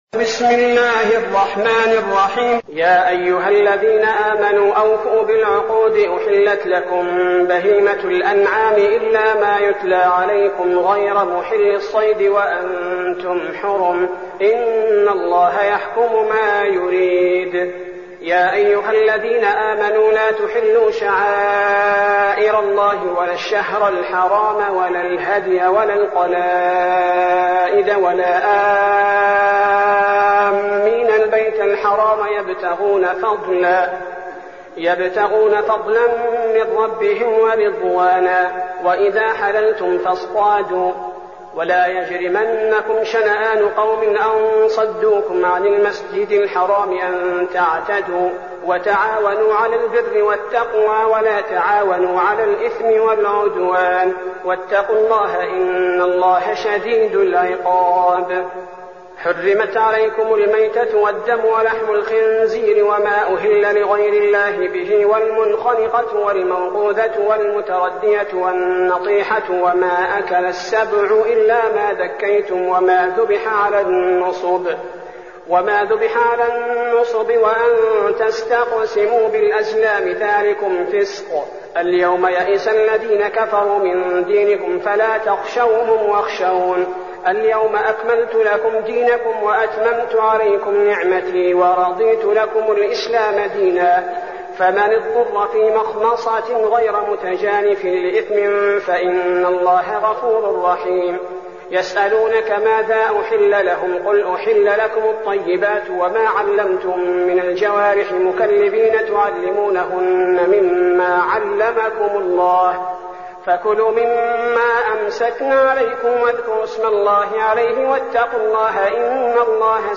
المكان: المسجد النبوي الشيخ: فضيلة الشيخ عبدالباري الثبيتي فضيلة الشيخ عبدالباري الثبيتي المائدة